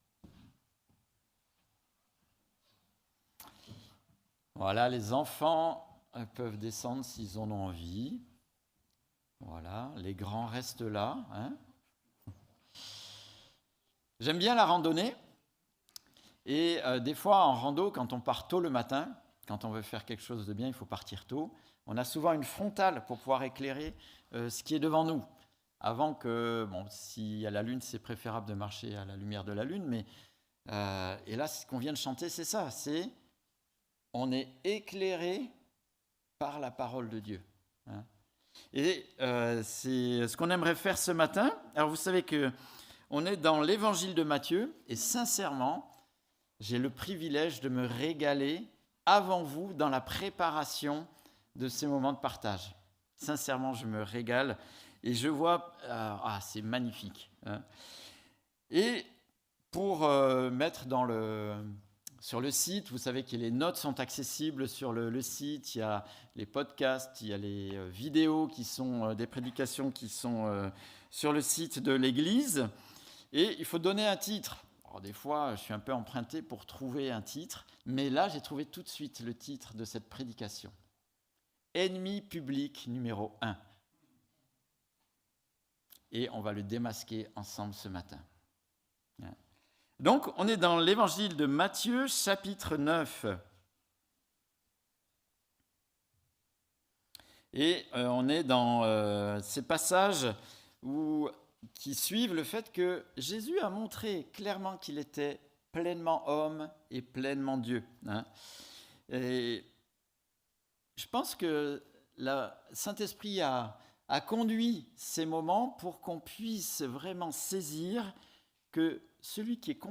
Prédication donnée à l'église Lausanne-Renens AB